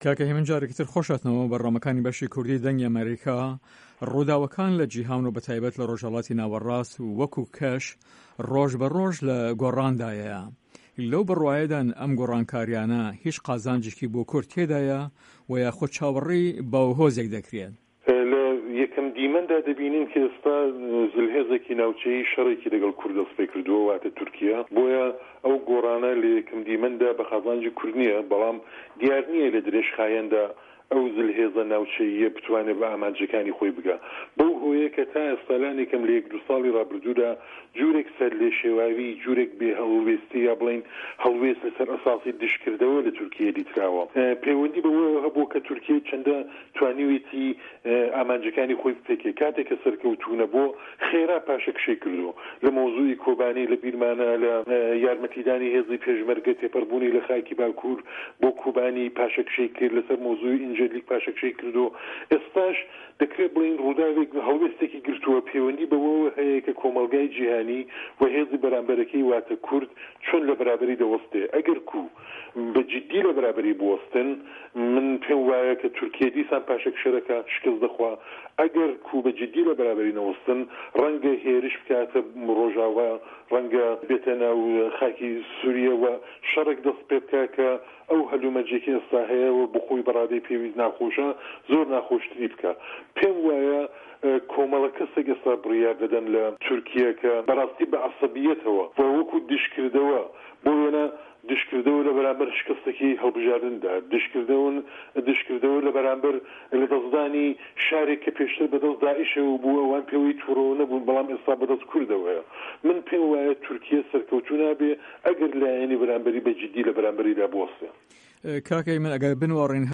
هه‌ڤپه‌یڤینێکدا